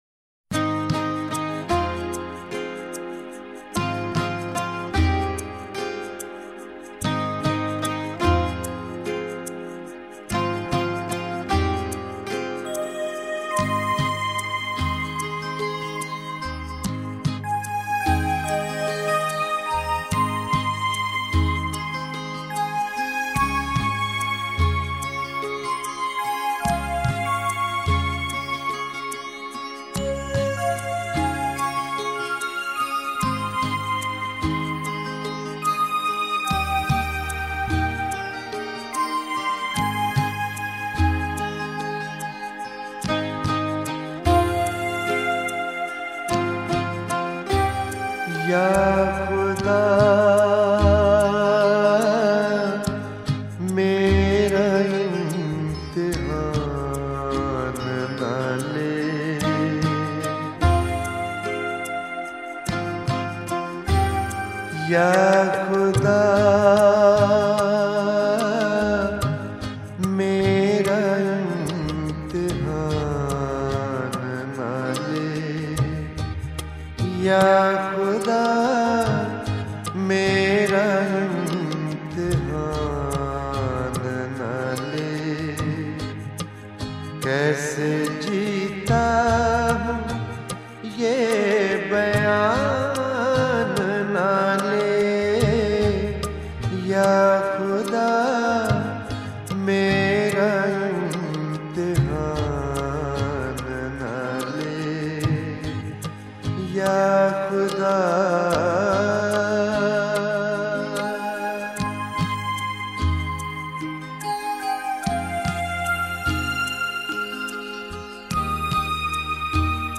Ghazal